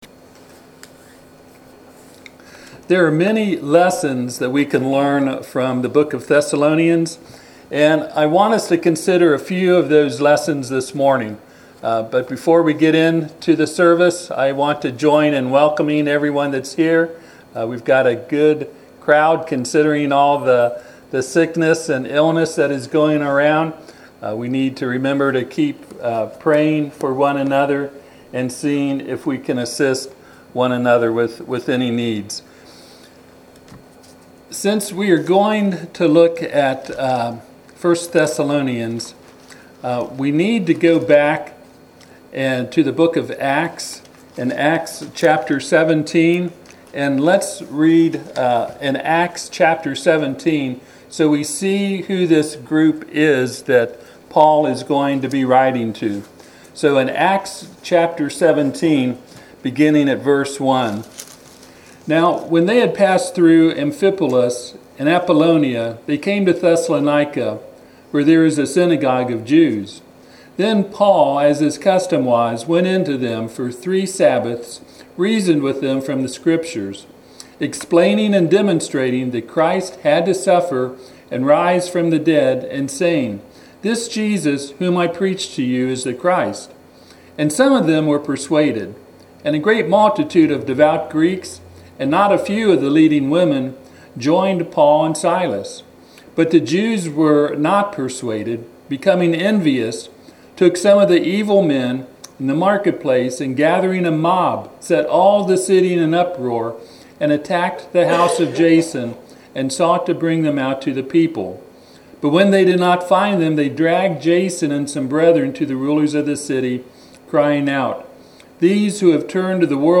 Hopeful and Helpful Preacher